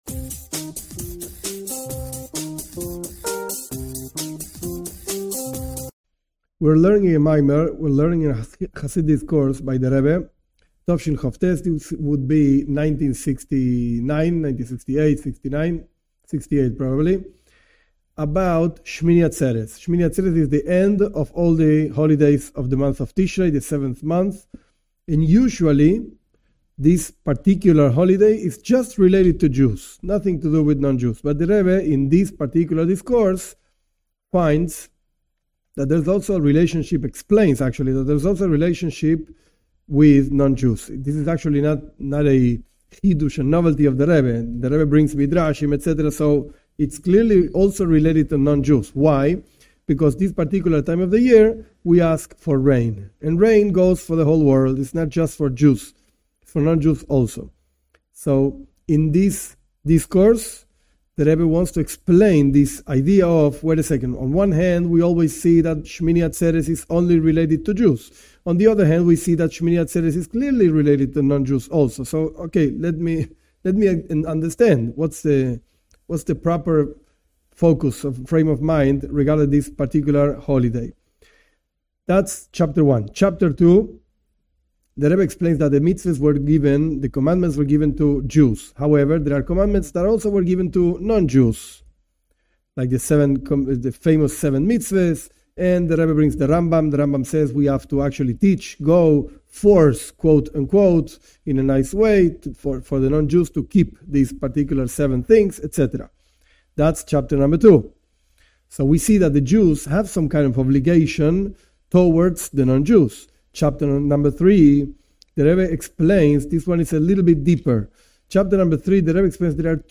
This is a chassidic discourse by the Rebbe, Rabi Menachem Mendel Schneerson, of the year 1968. In this discourse the Rebbe explains that there are two aspects of Judaism: one for Jews and one for non-Jews. This expresses itself in the Torah, in the Mitzvoth.